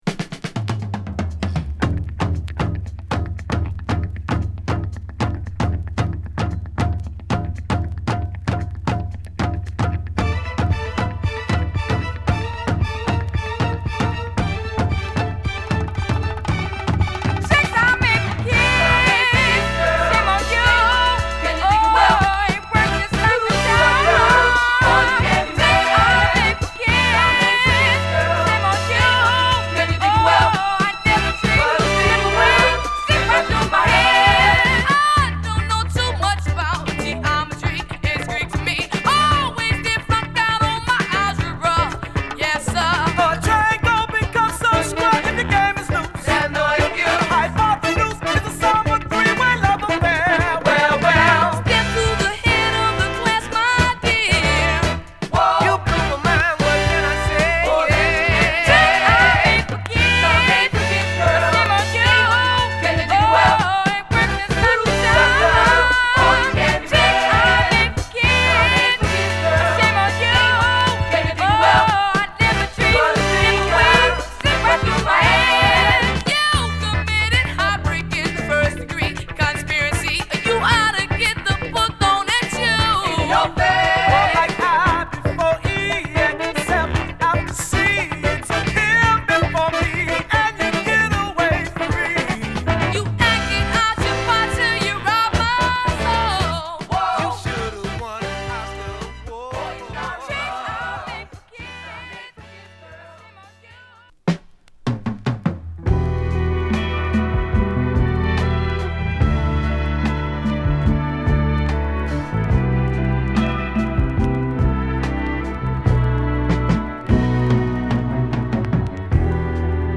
シカゴ発のキッズ・スウィートソウル！